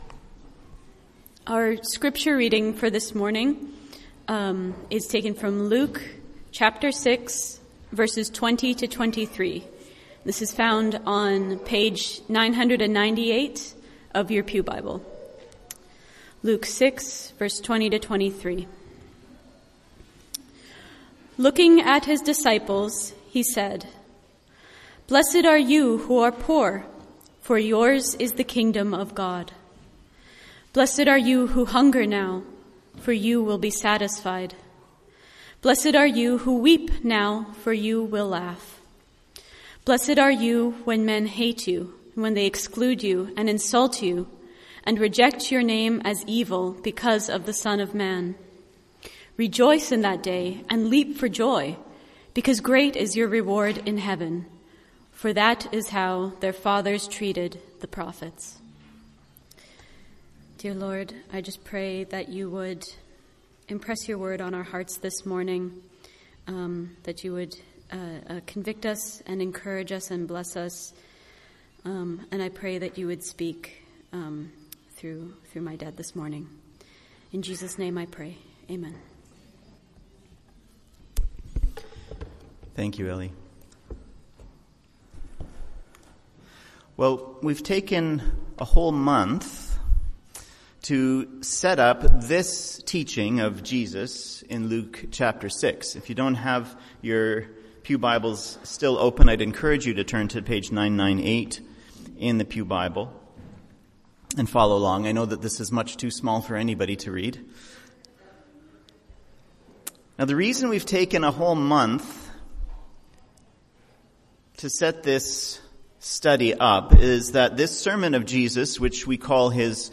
MP3 File Size: 20.2 MB Listen to Sermon: Download/Play Sermon MP3